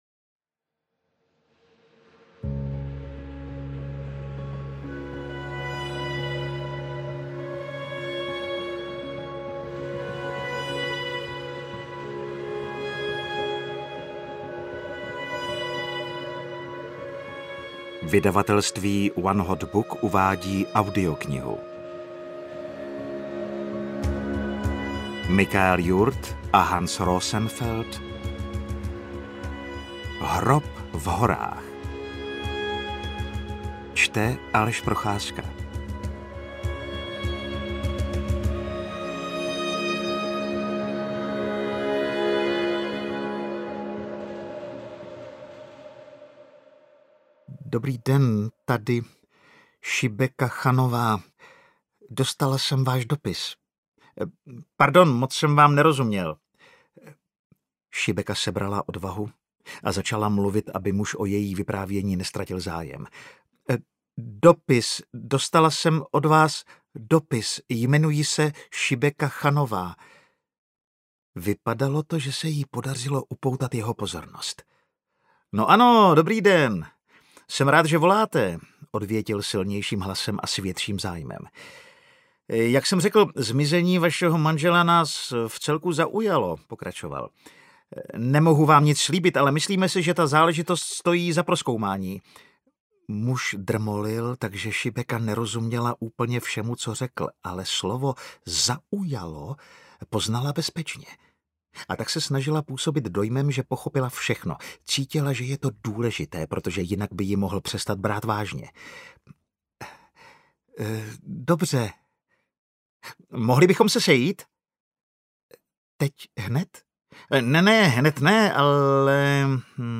Hrob v horách audiokniha
Ukázka z knihy